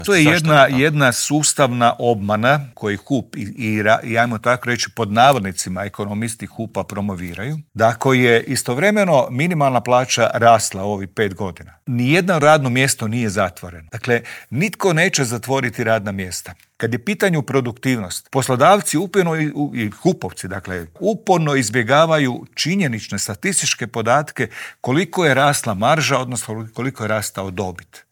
Intervjuu tjedna Media servisa